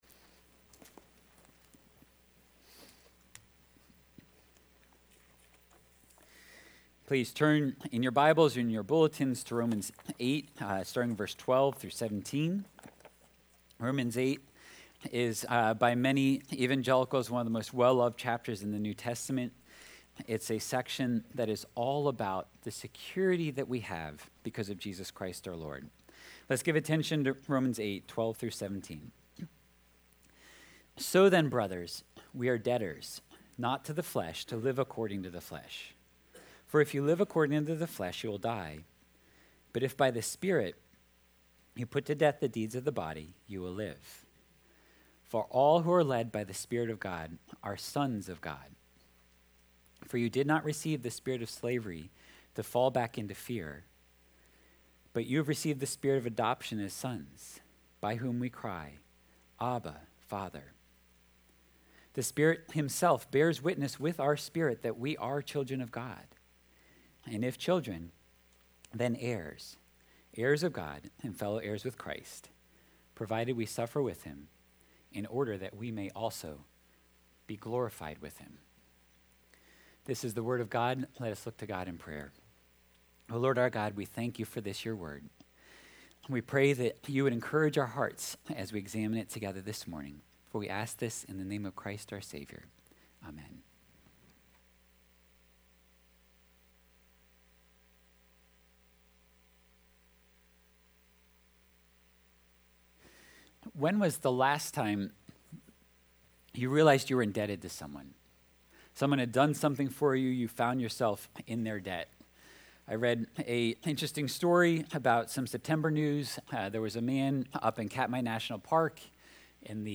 Sermons – Page 8 – New Life Presbyterian Church (PCA) | York, PA
Sunday Worship – November 24 of 2024 – “The Cultural Mandate”